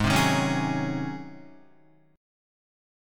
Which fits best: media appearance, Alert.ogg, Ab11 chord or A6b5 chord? Ab11 chord